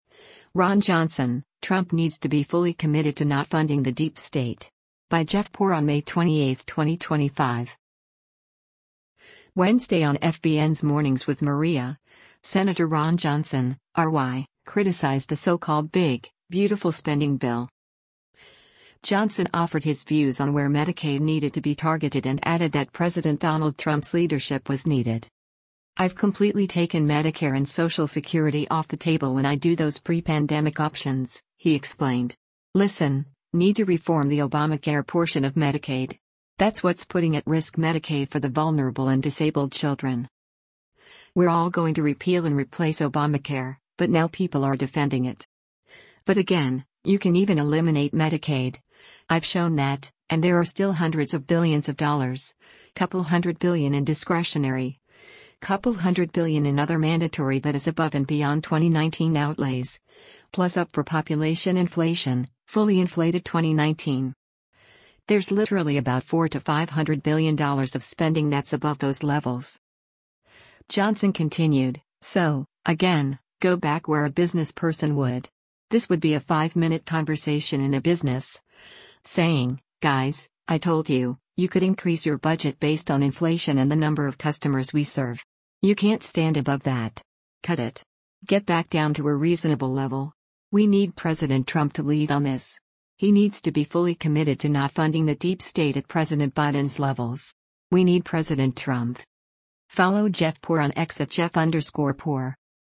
Wednesday on FBN’s “Mornings with Maria,” Sen. Ron Johnson (R-WI) criticized the so-called “Big, Beautiful” spending bill.